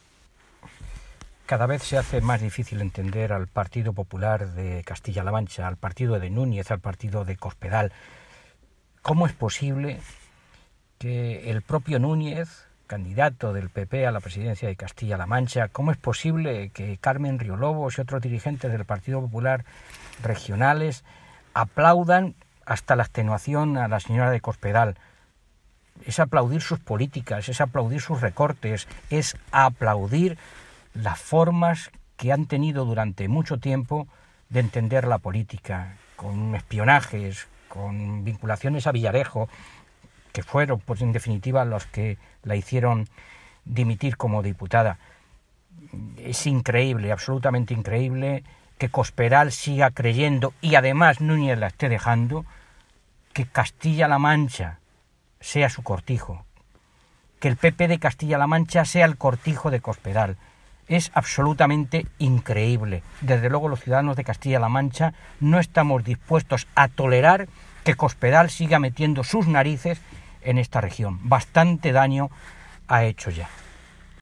Toledo, 20 de enero de 2019.- El diputado del Grupo socialista, Fernando Mora, ha calificado como de muy significativo que el presidente regional del PP, Paco Núñez, “se haya abrazado hoy de nuevo” a su antecesora en el cargo, María Dolores de Cospedal, en la convención nacional que los ‘populares’ celebran en Madrid.
Cortes de audio de la rueda de prensa